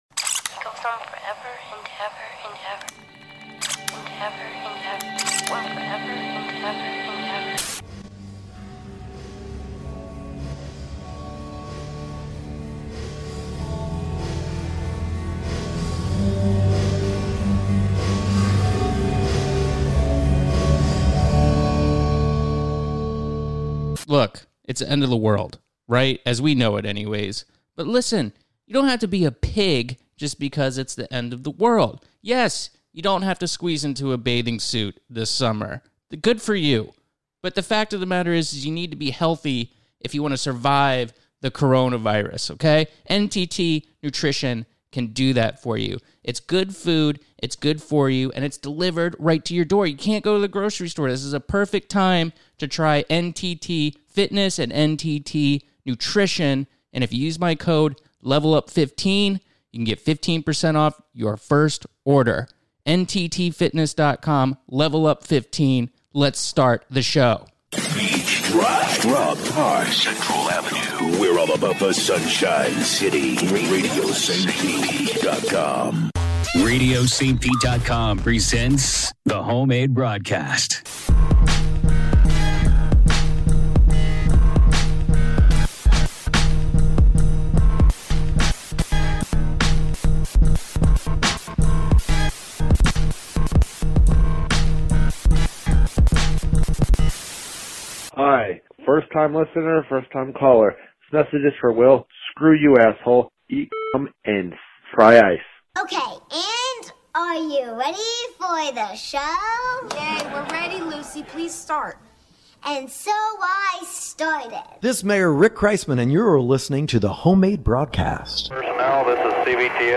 he Homemade Broadcast this week: back in the home studio marveling at modern technology, Florida Gov. Ron DeSantis catches hell for a mistake & we say goodbye to Bernie Sanders.